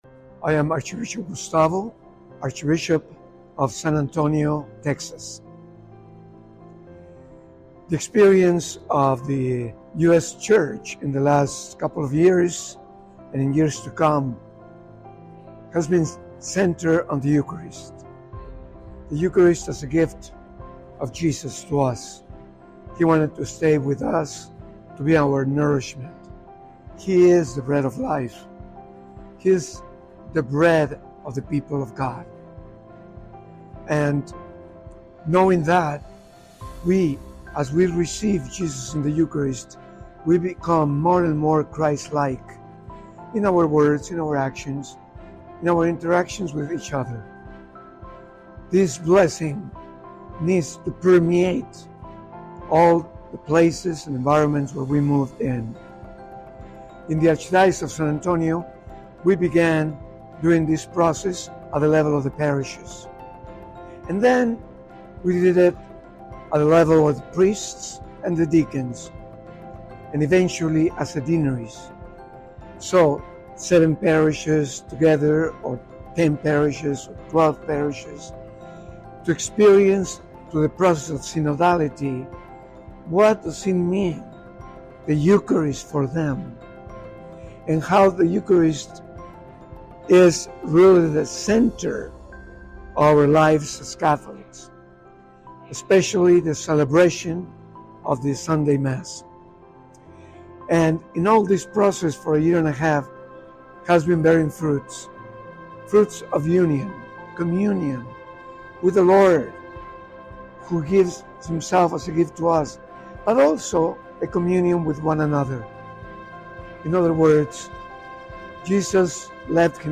(full interview length) (radio ready 4 min)